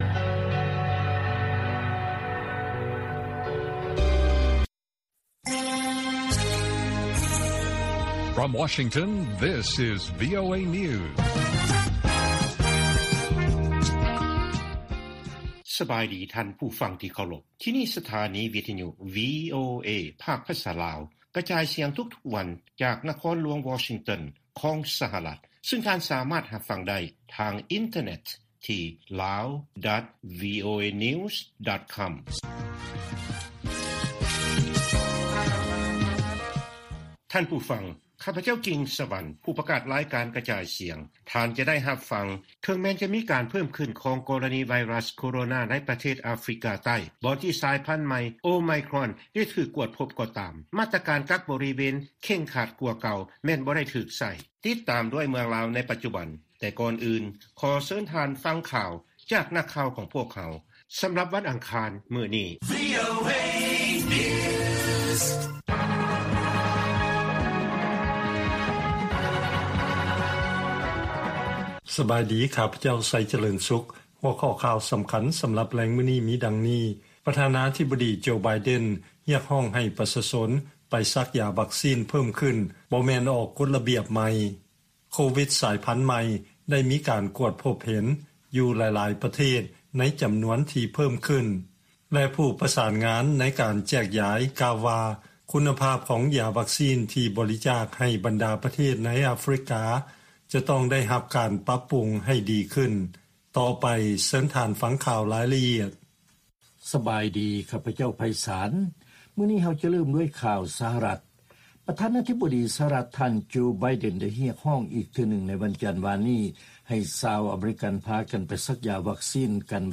ລາຍການກະຈາຍສຽງຂອງວີໂອເອ ລາວ: ອົງການໄອຍະການ ສາມາດດຳເນີນມາດຕະການ ບັງຄັບຕິດຕາມ ການຕັດສິນຂອງສານປະຊາຊາຊົນລາວໄດ້ຫນ້ອຍກວ່າ 8 ເປີເຊັນ